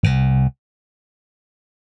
Index of /RoBKTA Sample Pack Supreme/BASSES
02_Funky.wav